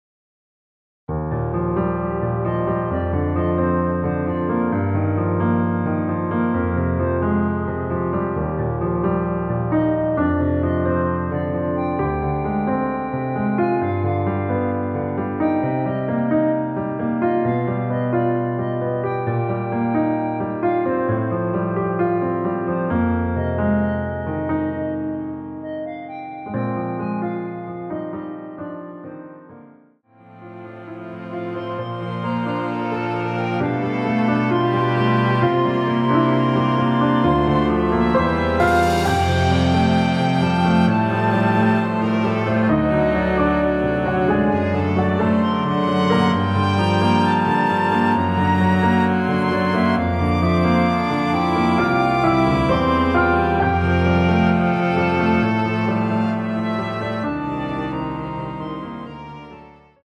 원키에서(-1)내린 멜로디 포함된 MR입니다.
Eb
◈ 곡명 옆 (-1)은 반음 내림, (+1)은 반음 올림 입니다.
앞부분30초, 뒷부분30초씩 편집해서 올려 드리고 있습니다.
중간에 음이 끈어지고 다시 나오는 이유는